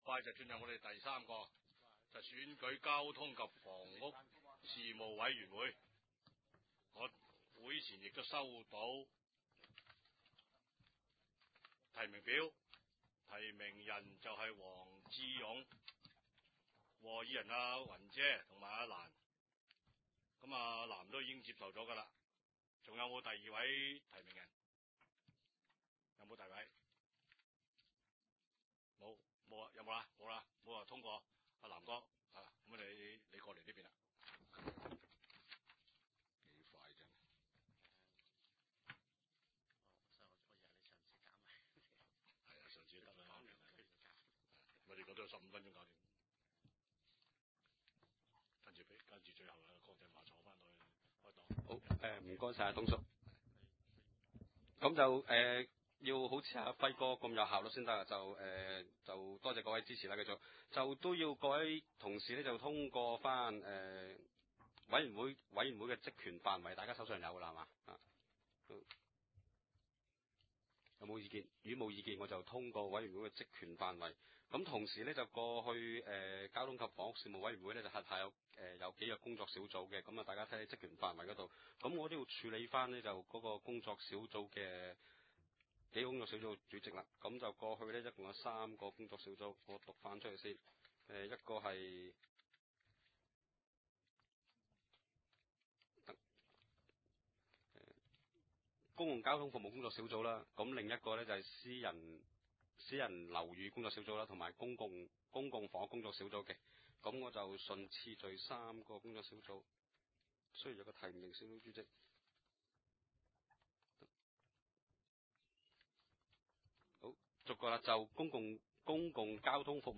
通及房屋事務委員會特別會議議程
地點：九龍長沙灣道303號長沙灣政府合署4字樓
深水埗區議會會議室